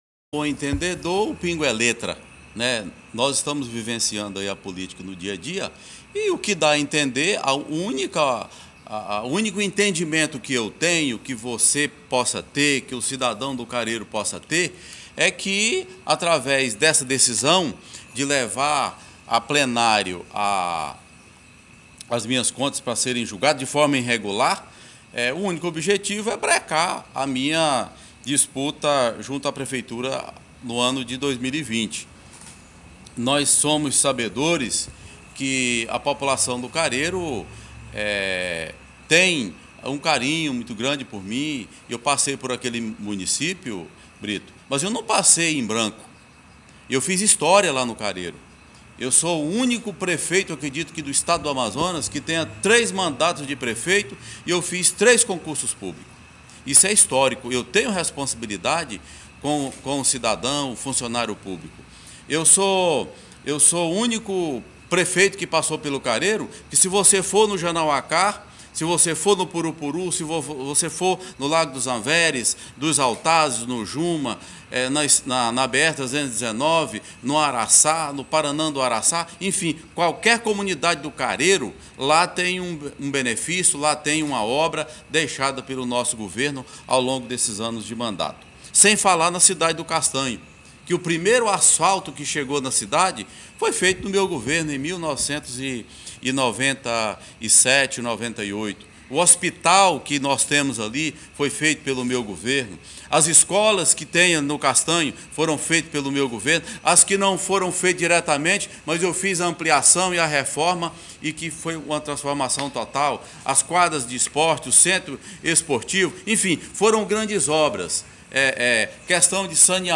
Resposta Joel Lobo: